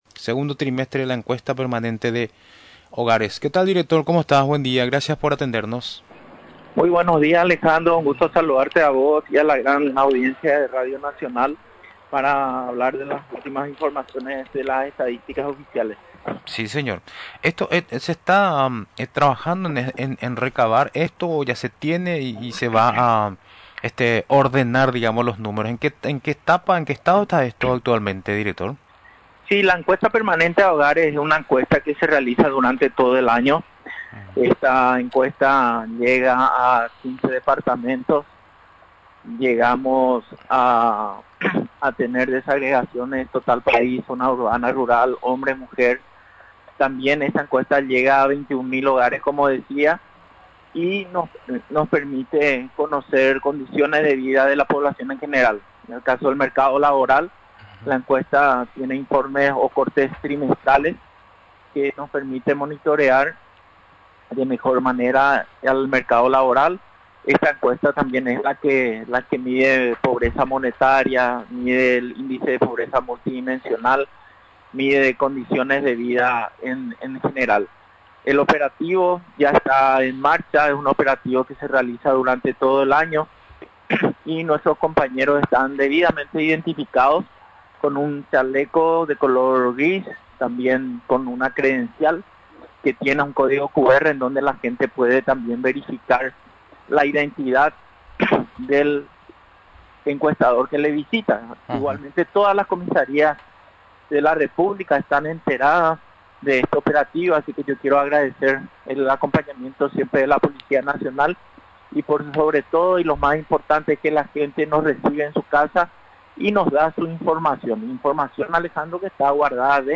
Durante la entrevista en Radio Nacional del Paraguay, refirió que la institución a su cargo, realiza este operativo durante todo el año.